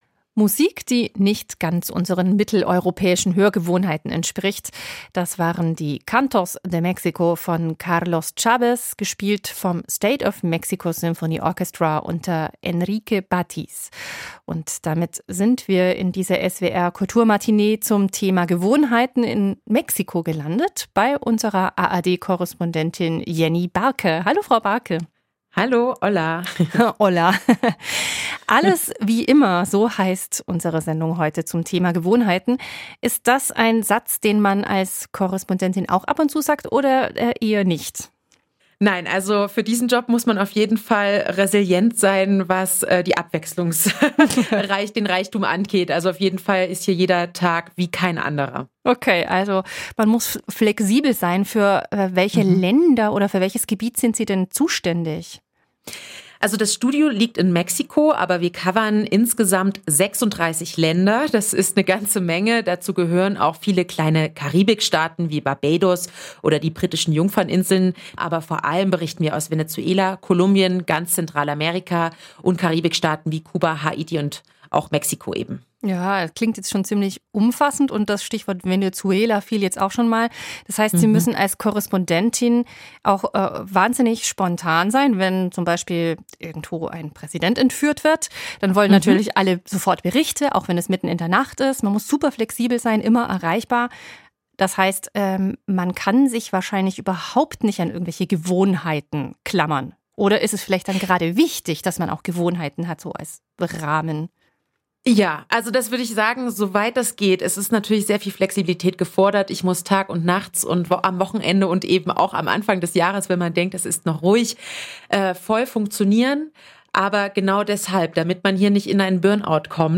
"Jeder Tag wie kein anderer" – Eine Korrespondentin erzählt
Das Interview führte